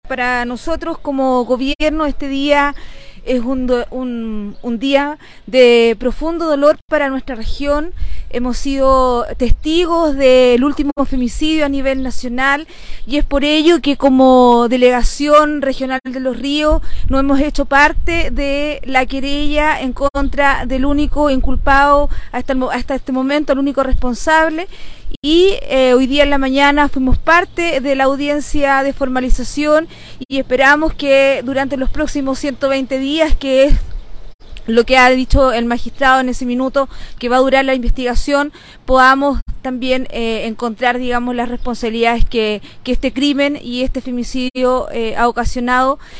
El anuncio lo realizó en medio de una actividad en conmemoración del Día Internacional por la Eliminación de la Violencia Hacia la Mujer, realizado en dependencias del Centro de Reeducación de Hombres del Servicio Nacional de la Mujer e Igualdad de Género (Sernameg), en donde participó junto a los secretarios regionales ministeriales que integran el gabinete regional.